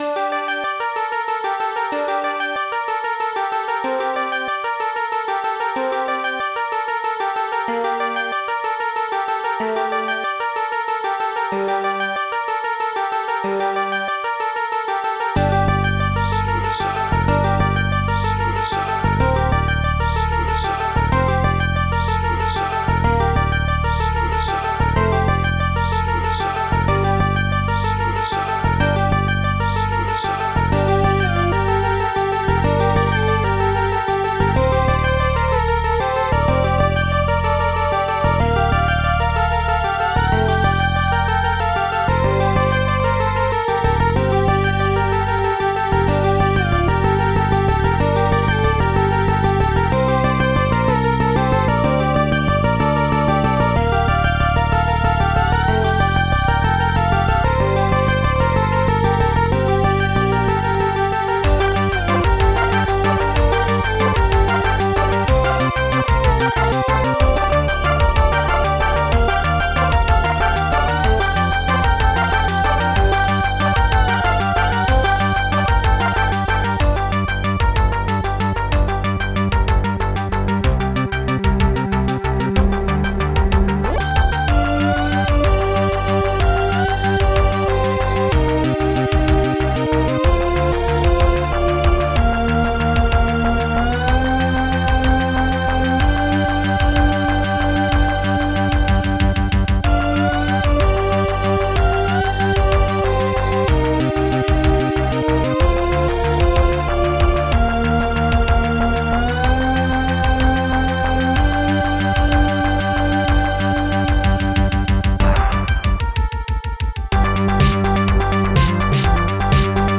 Protracker and family